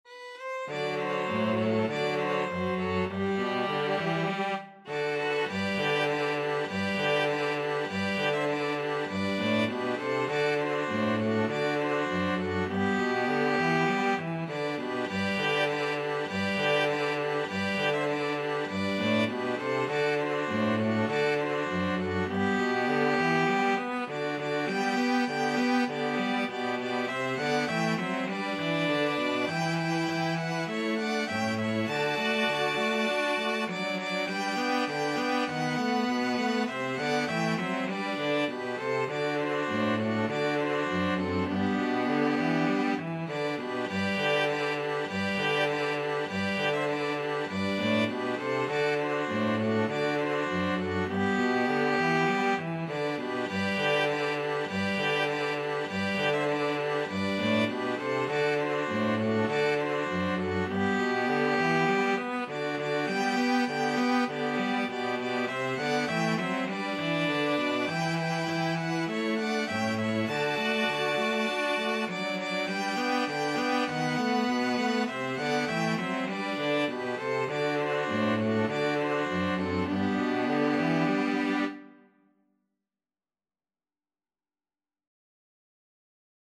Violin 1Violin 2ViolaCello
2/2 (View more 2/2 Music)
String Quartet  (View more Easy String Quartet Music)
Traditional (View more Traditional String Quartet Music)